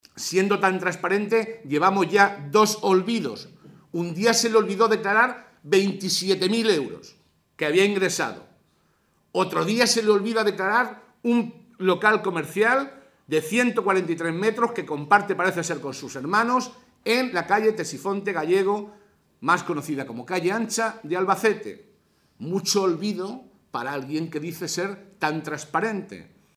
José Molina, portavoz del Grupo Parlamentario Socialista
Cortes de audio de la rueda de prensa